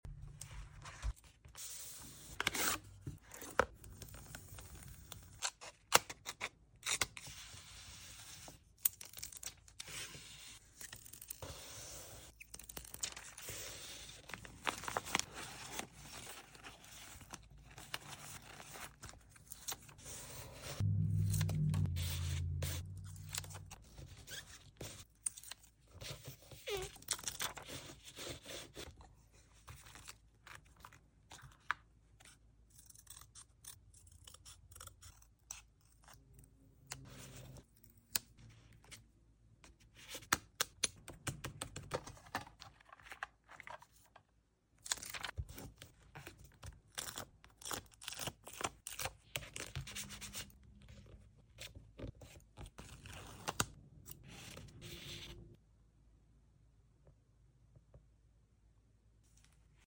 ASMR | journal with me sound effects free download